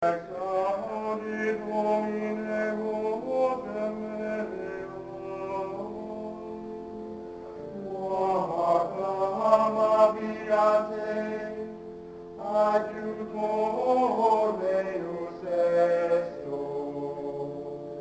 Tags: Gregorian Chants Gregorian sounds